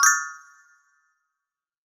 Negative Bell Bling Game Sound.wav